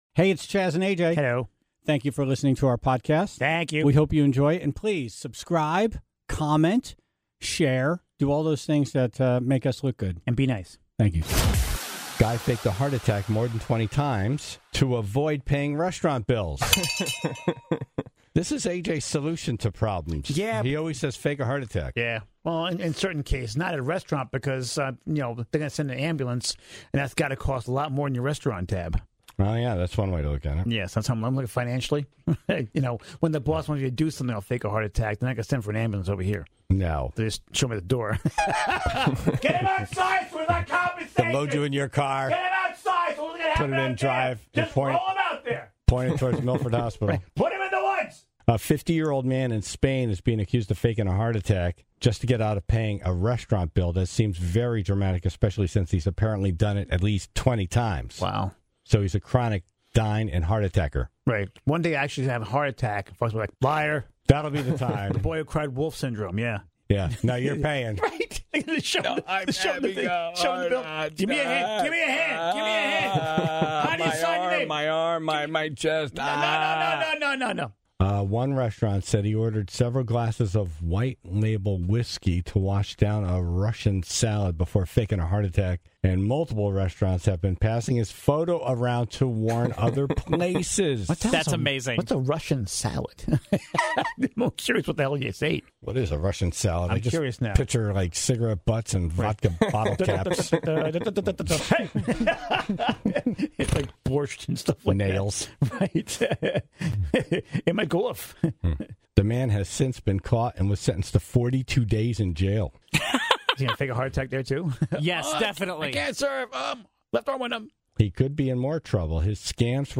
(4:45) A report from Florida spotlighted a filthy restaurant, shut down for numerous citations of rodents and droppings. Then, the Tribe called in their stories of dirty dining.